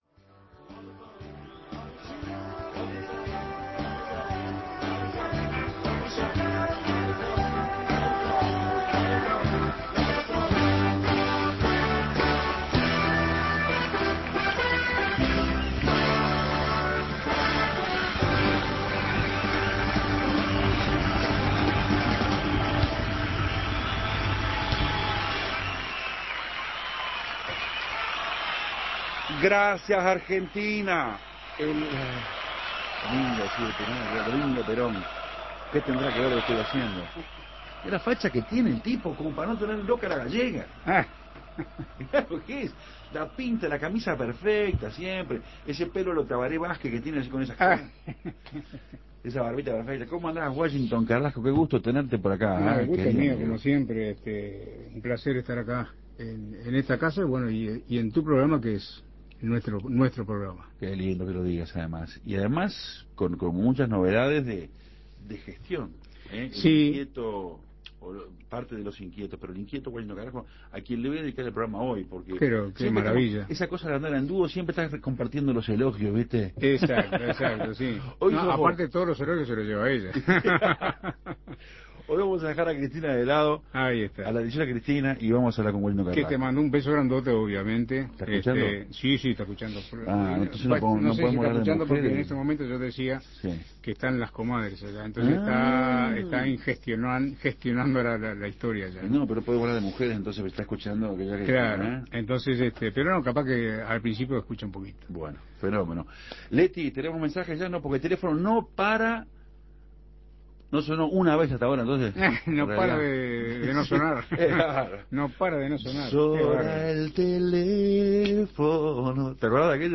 Washington Carrasco contó historias de fonoplateas. Con la guitarra mediante, Café Torrado se quedó con la palabra y compartió una Colmena de proyectos.